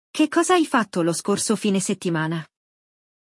Acompanhe um diálogo divertido entre duas amigas: uma passou o sábado e o domingo se divertindo no karaokê, enquanto a outra preferiu ficar em casa estudando.